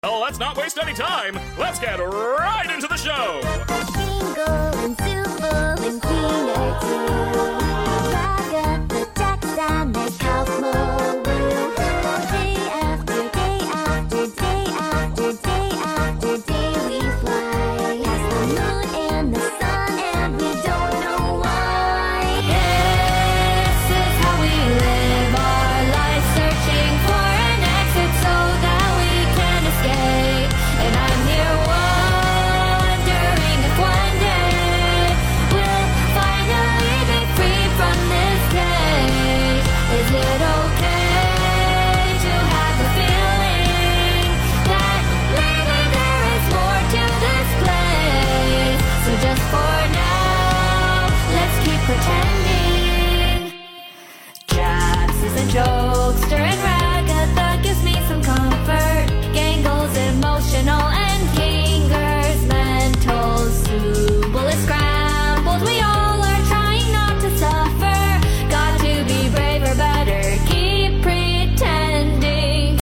parody